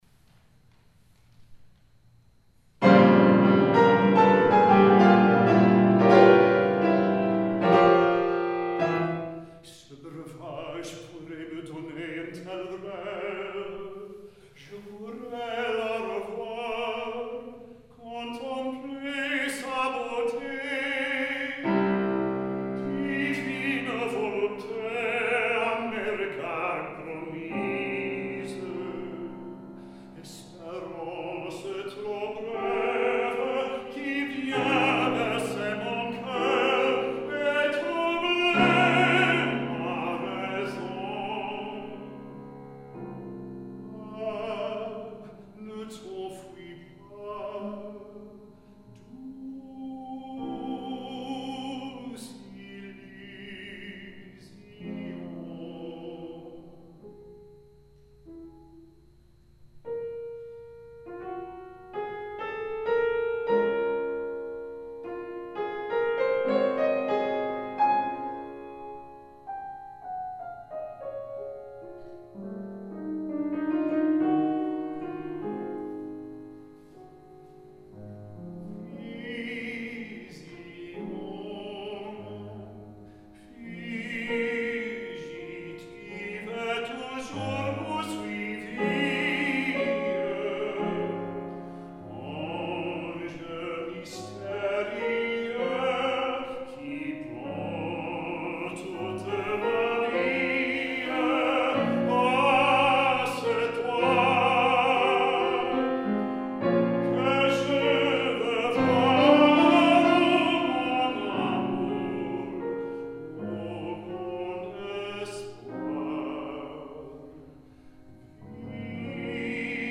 This music is all from live performances at various locations.